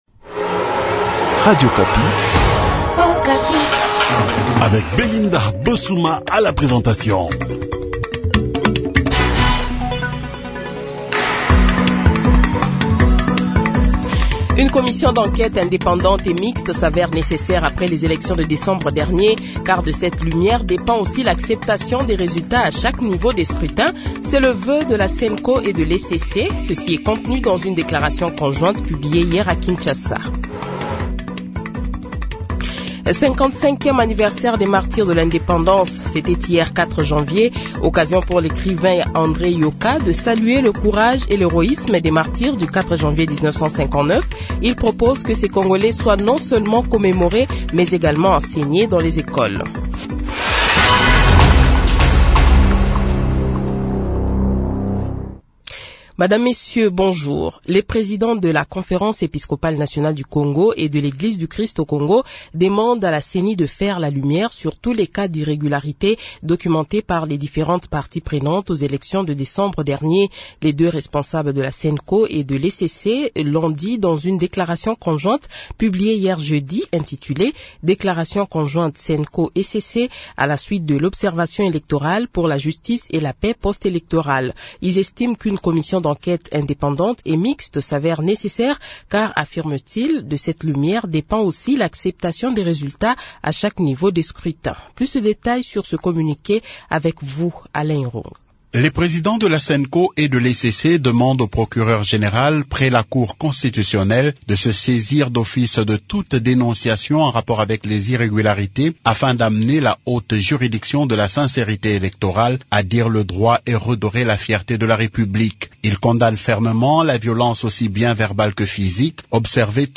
Journal Francais Matin
Le Journal de 7h, 05 Janvier 2024 :